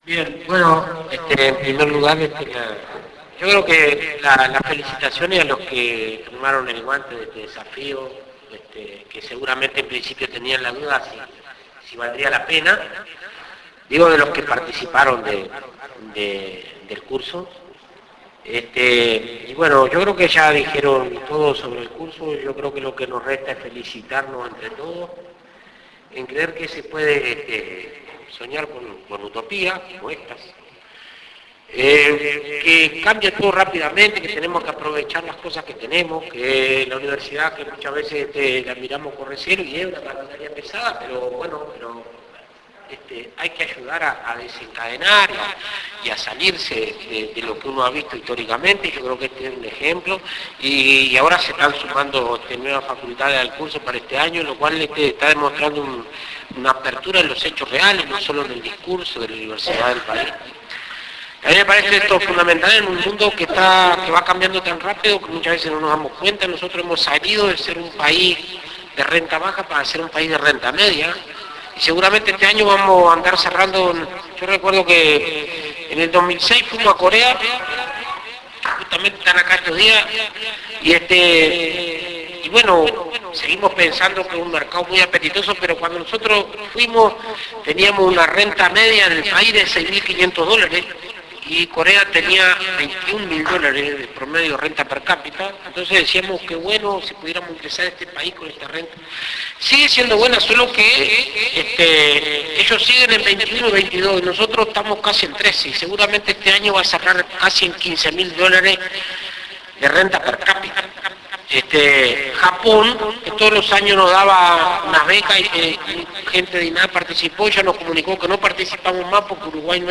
Presidente de INAC
Dr. Alfredo Fratti Entrega de diplomas en Posgrado EIC  5:26 Mp3